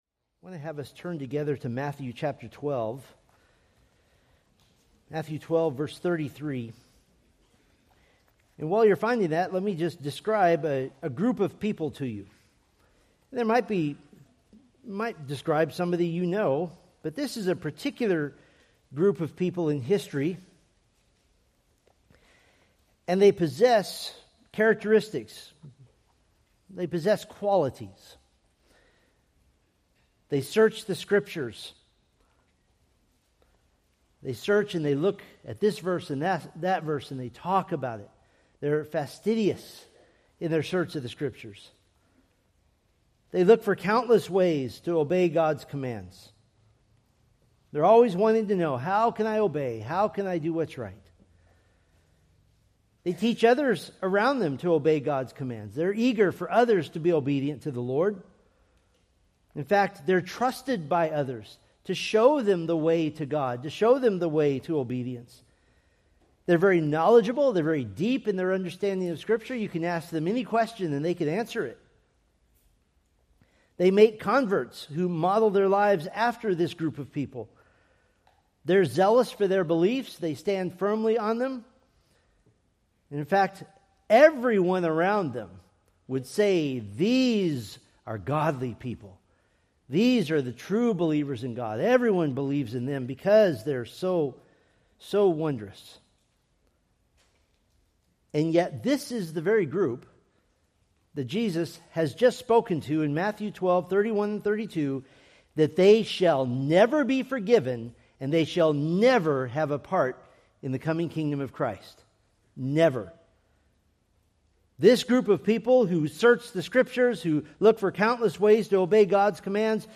Preached November 23, 2025 from Matthew 12:32-37